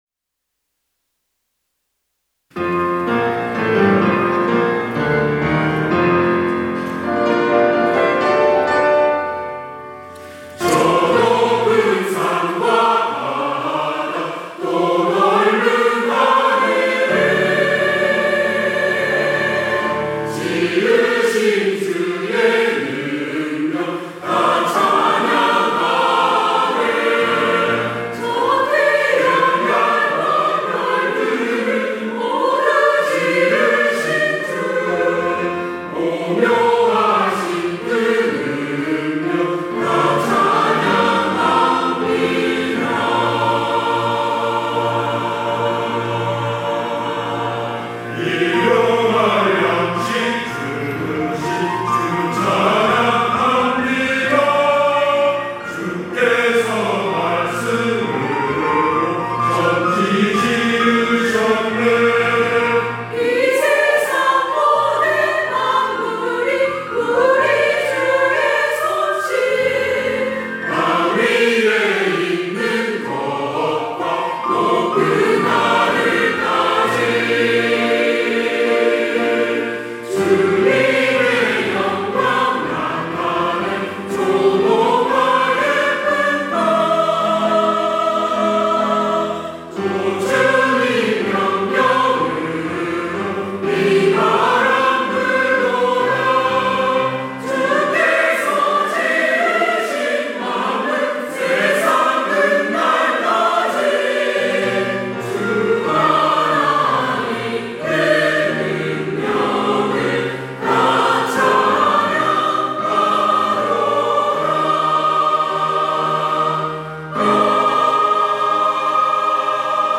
시온(주일1부) - 하나님의 전능하심을 찬양
찬양대